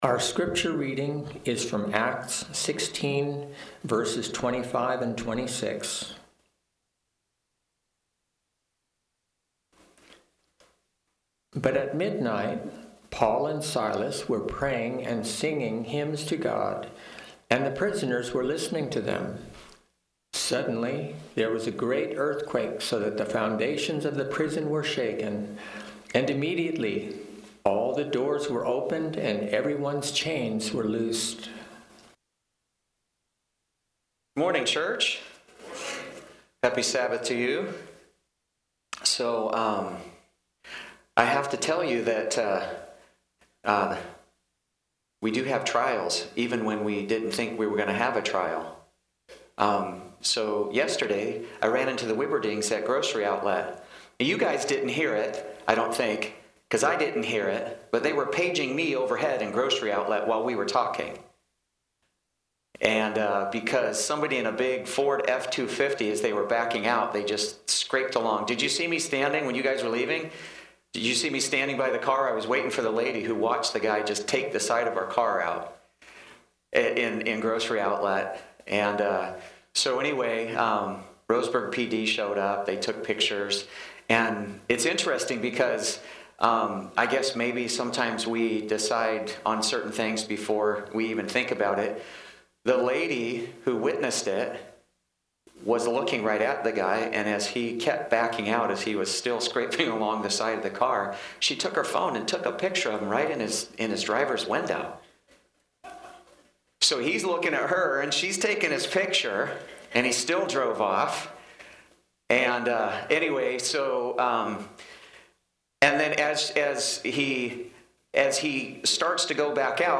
Sermons and Talks